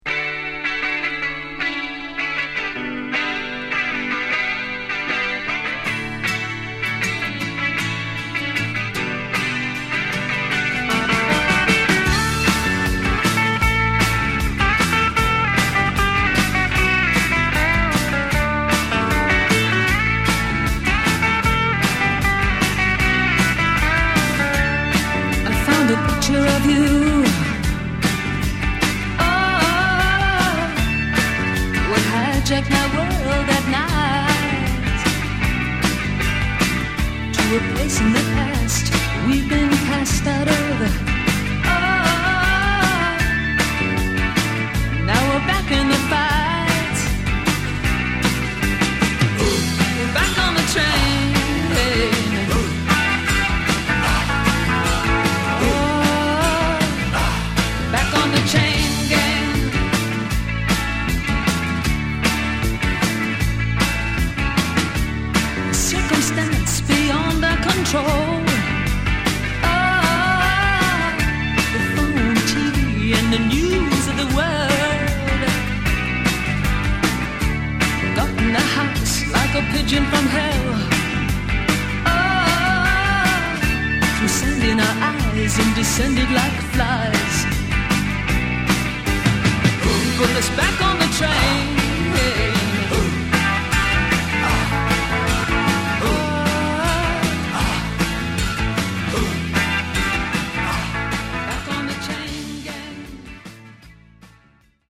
Genre: Modern Rock
Terrific, almost-retro, power pop hit... not to be missed!